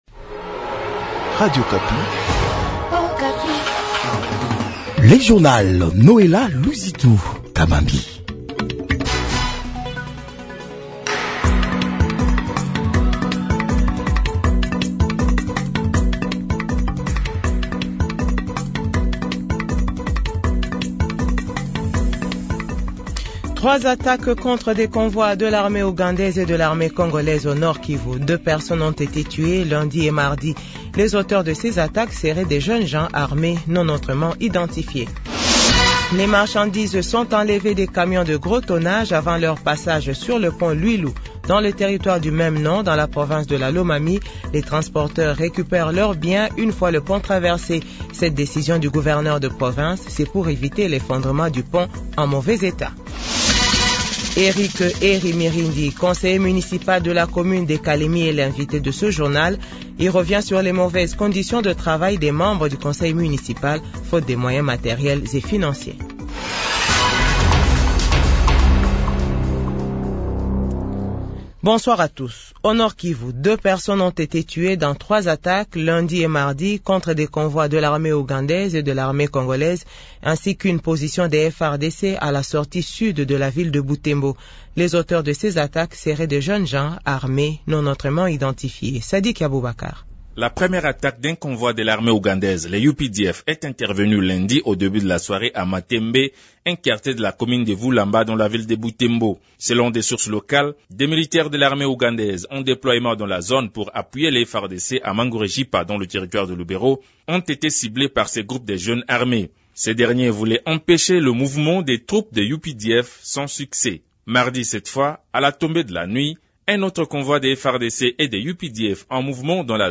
JOURNAL FRANCAIS 18H00